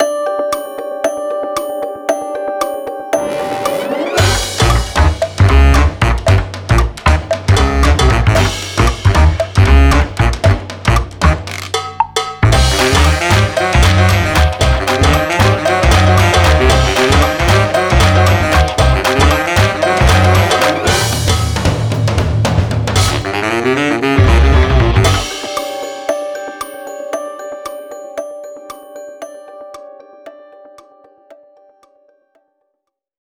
Funny Sitcom Theme.mp3